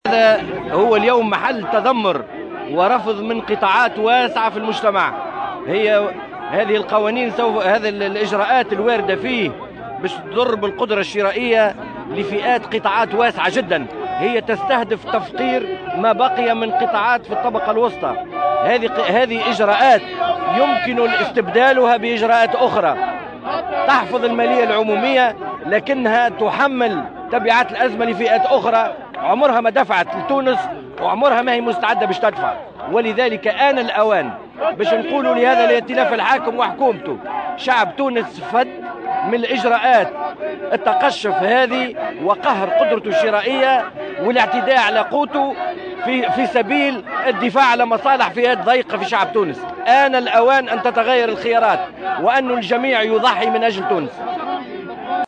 أكد القيادي في الجبهة الشعبية، زياد لخضر، في تصريح لـ "الجوهرة اف أم" اليوم على هامش فعاليات لإحياء الذكرى السابعة للثورة التونسية، ضرورة استبدال اجراءات قانون المالية بإجراءات أخرى بدلا من تفقير الطبقة المتوسطة.